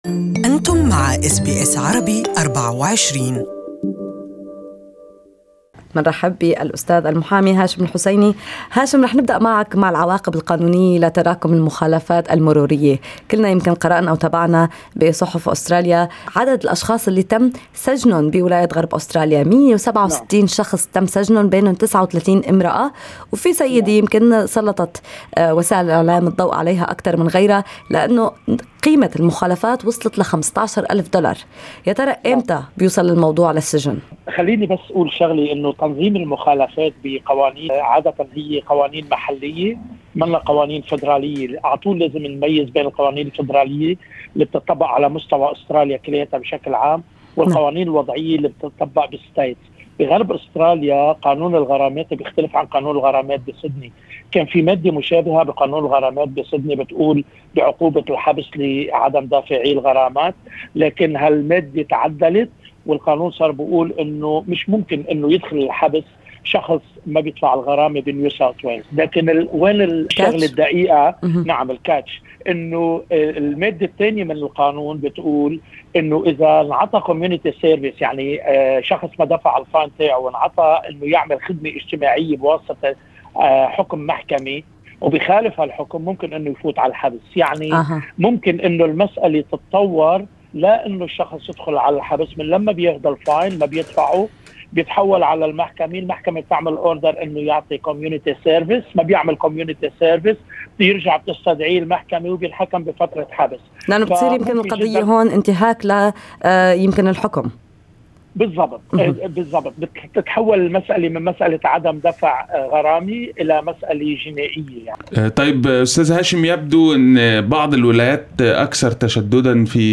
Morning in the interview with lawyer